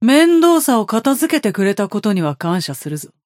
Yamato voice line - Thanks for taking care of Mendoza.